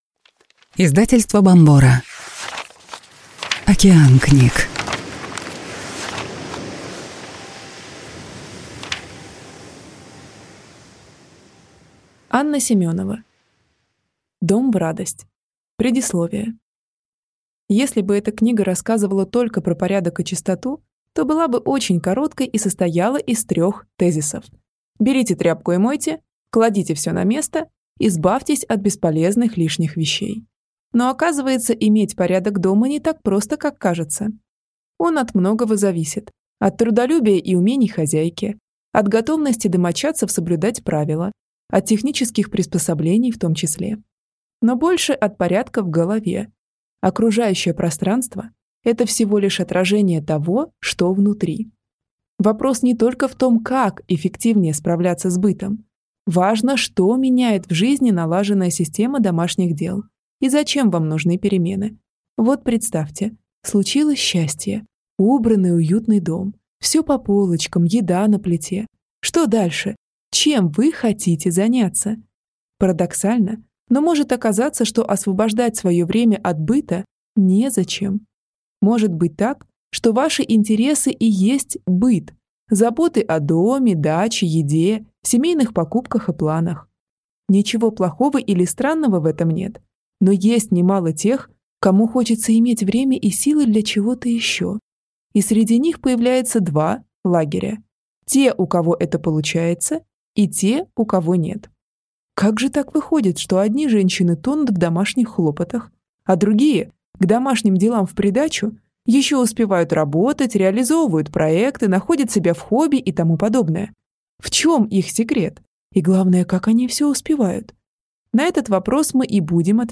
Аудиокнига Дом в радость. Как организовать быт, чтобы оставалось время на жизнь | Библиотека аудиокниг